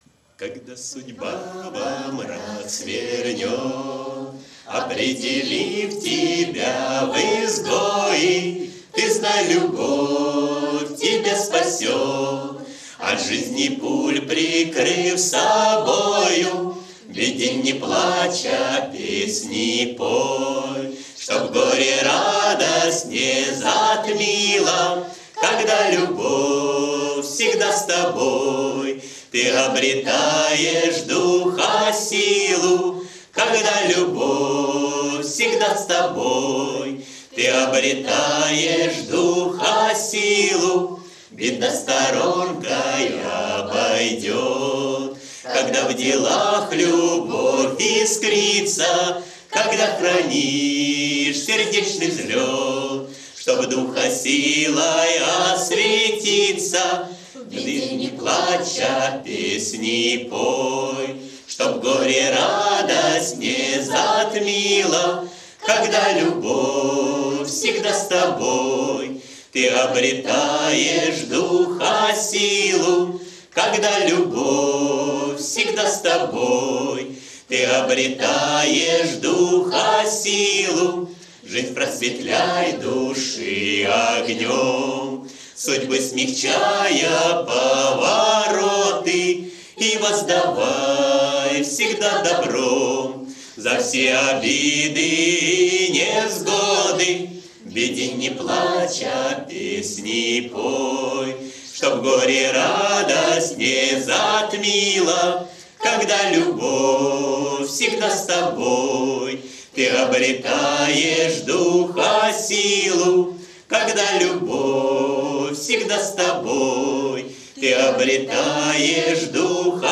кавер-версия на мотив марша 1929-30 гг.